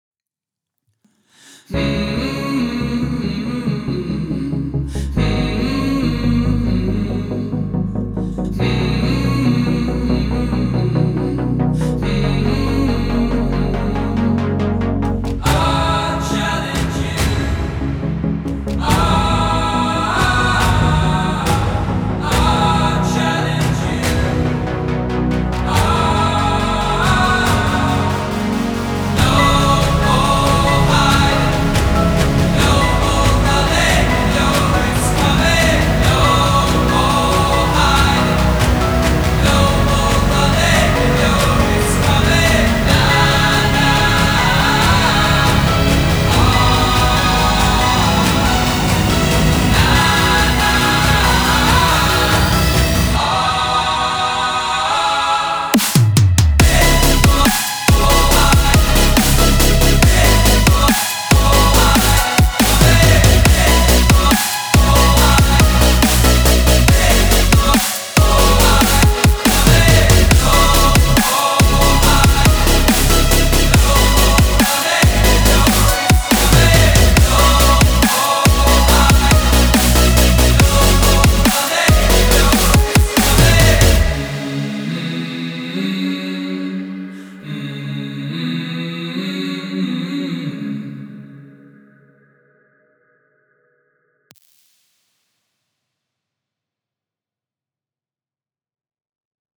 sample library of chants and shouts
Inside you will find various different tempo and keys of Chants, including Dry, Ambient, Megaphone, Reverb and Saturated effected versions.
Instead of the typical one lead line or one shot vocal with one voice recorded, these are all layered to perfection with full on crowd chants and shouts, that make the whole party start to jump!
Only the Chants, Shouts or Vocals used in the demos are from the pack.